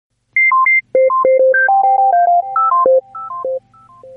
Categories: Messages - SMS